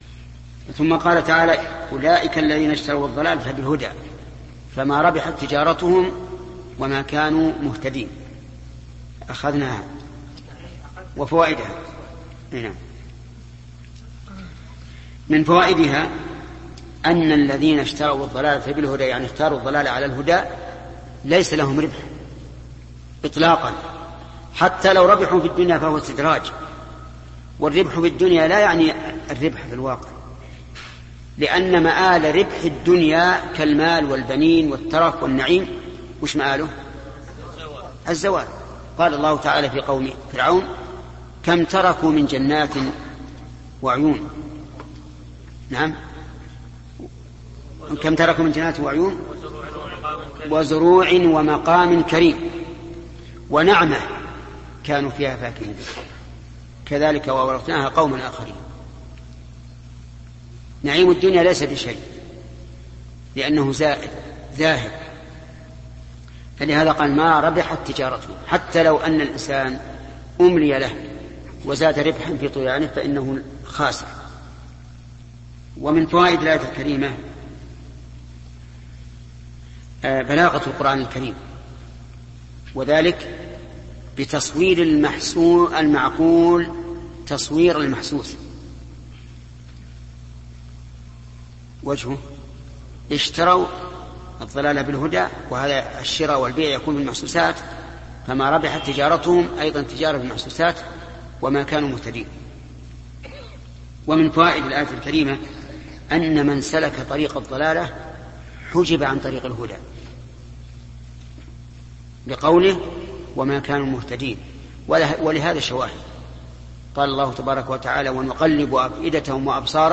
📌الشيخ محمد بن صالح العثيمين / تفسير القرآن الكريم